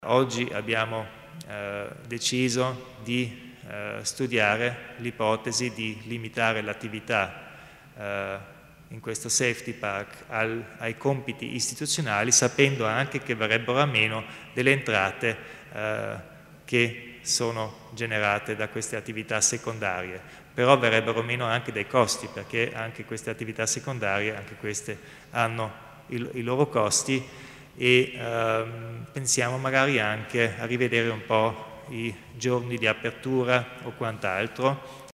Il Presidente Kompatscher illustra i cambiamenti per il Safety Park di Vadena